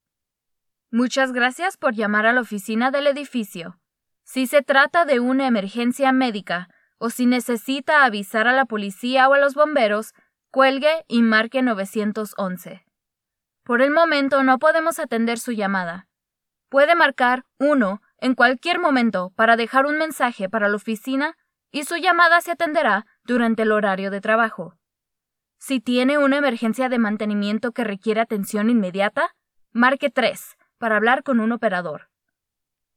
voice-over
Jong, Natuurlijk, Vriendelijk
Telefonie